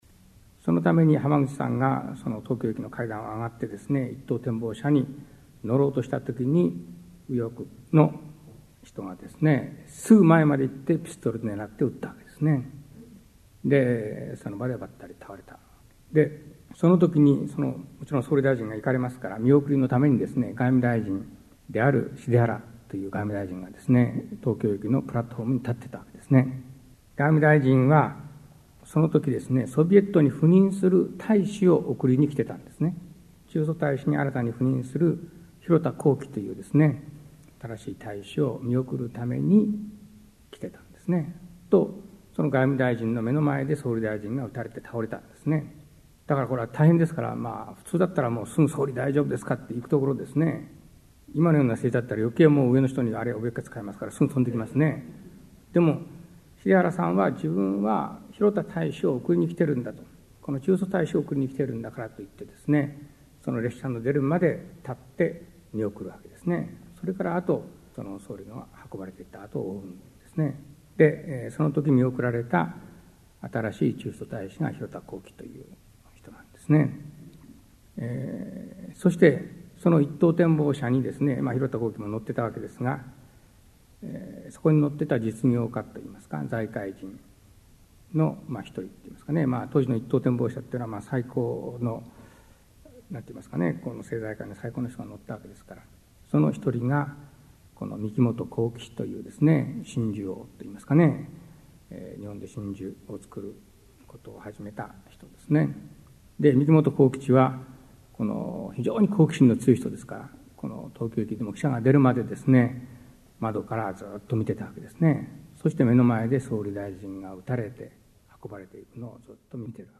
名だたる文筆家が登場する、文藝春秋の文化講演会。
（1989年7月7日 奈良市史跡文化センター 菊池寛生誕百周年記念講演会より）